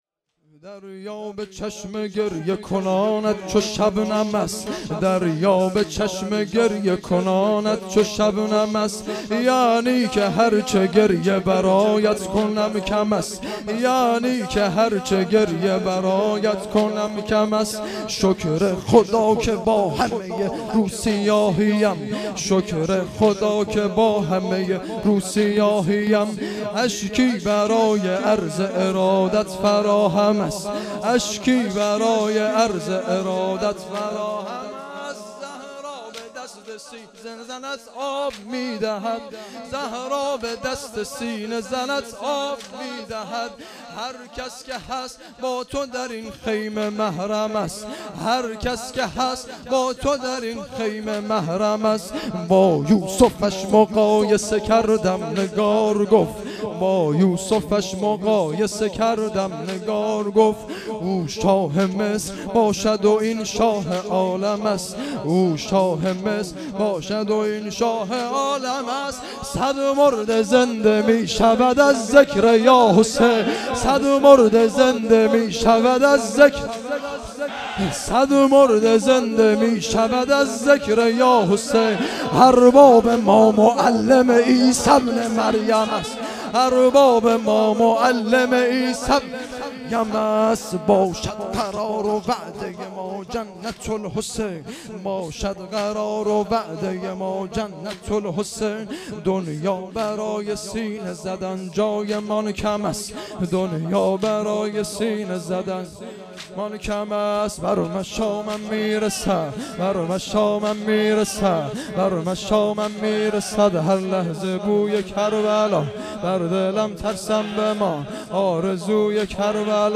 دهه اول محرم الحرام ۱۴۴۴